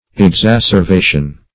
Search Result for " exacervation" : The Collaborative International Dictionary of English v.0.48: Exacervation \Ex*ac`er*va"tion\, n. [L. exacervare to heap up exceedingly.